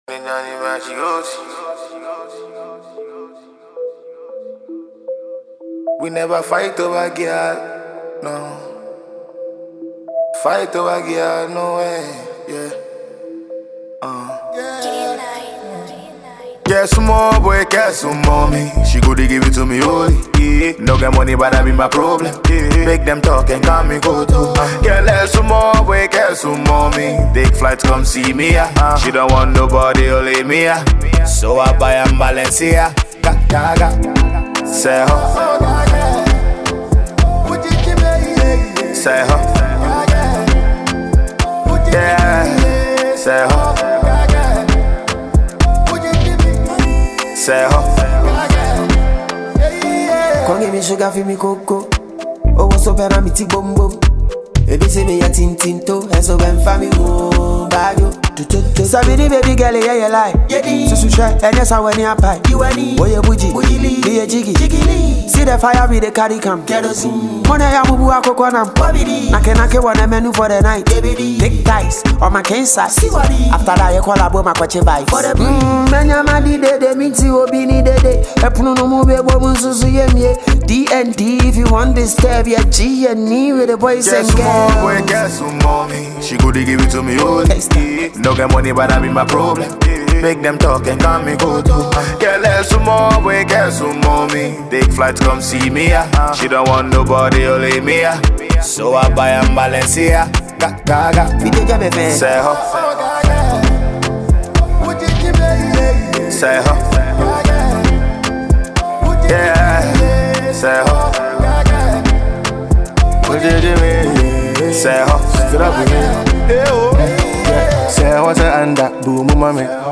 a dope jam. Enjoy this well-produced track.